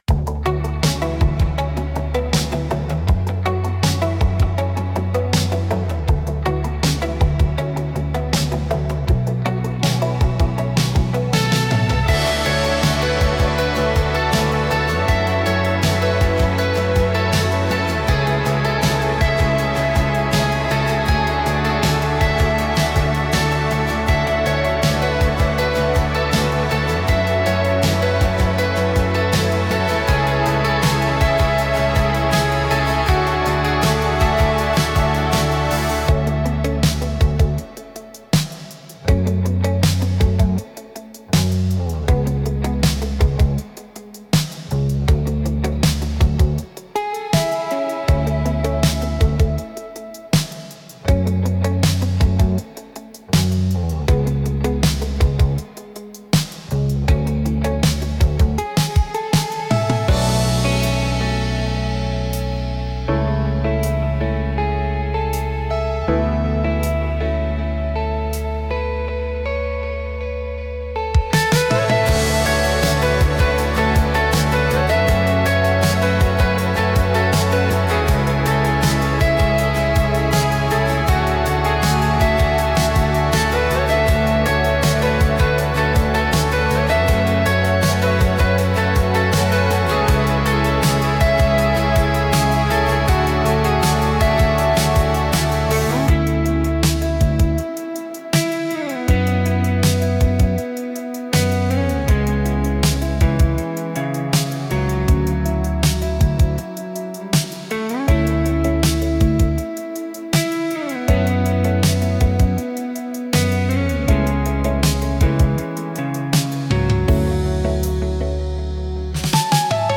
ドリームポップは、繊細で幻想的なサウンドが特徴のジャンルです。
静かで美しい音の重なりが心地よく、感性を刺激しながらも邪魔にならない背景音楽として活用されます。